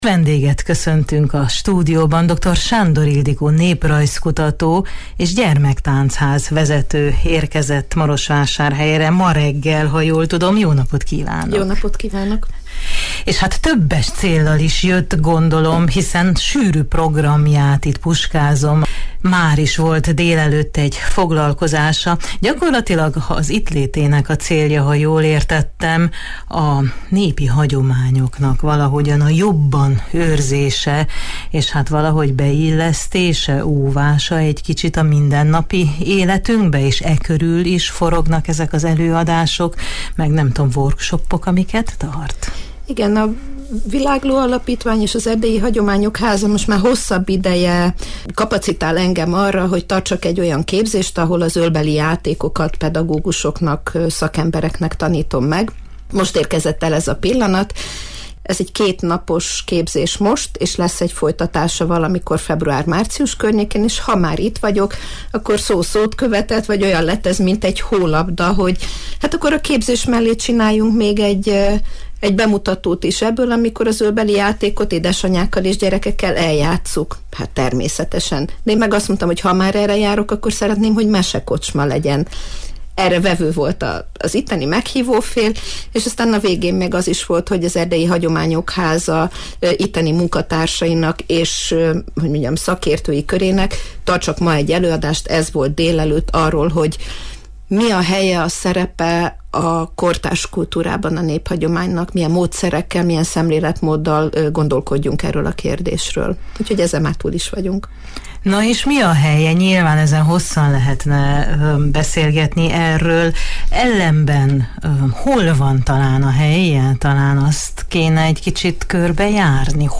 a Marosvásárhelyi Rádió vendége is volt